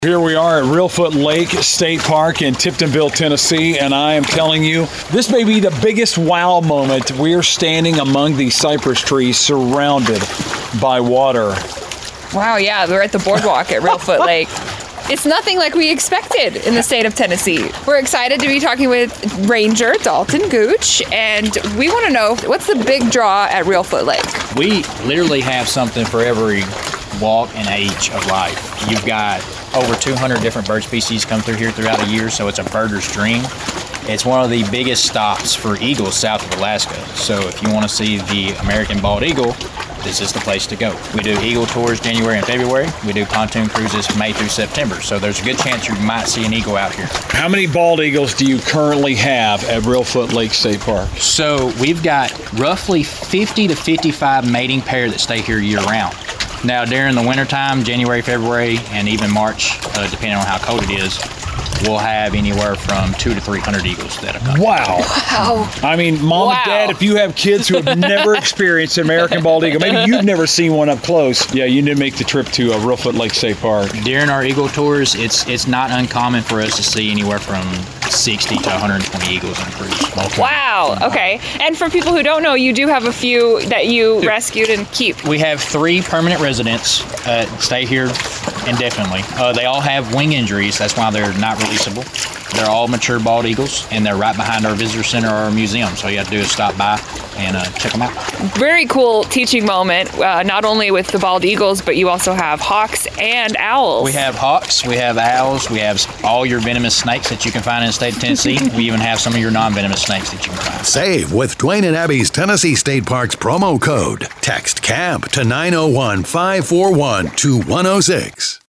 The sound of the waves lapping against the Cypress Trees made for a lovely chat about the most surprising park we’ve visited so far, Reelfoot Lake State Park!